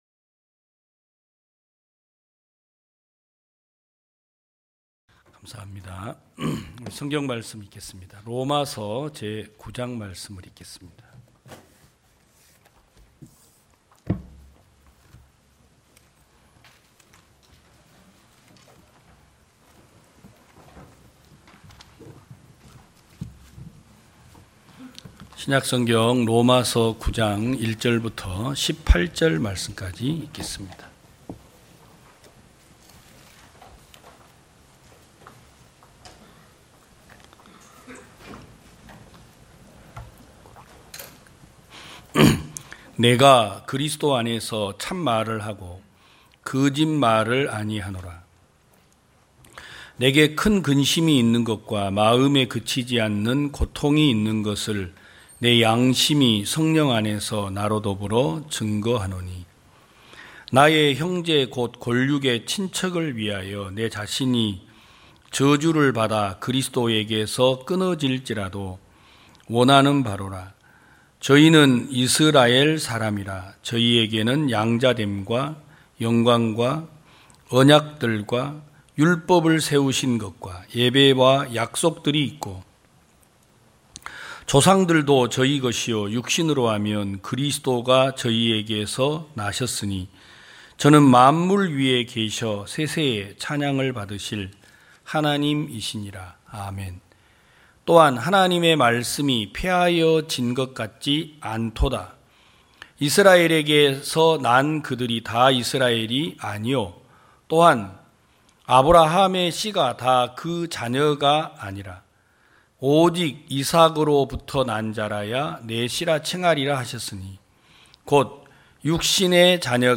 2022년 09월 18일 기쁜소식부산대연교회 주일오전예배
성도들이 모두 교회에 모여 말씀을 듣는 주일 예배의 설교는, 한 주간 우리 마음을 채웠던 생각을 내려두고 하나님의 말씀으로 가득 채우는 시간입니다.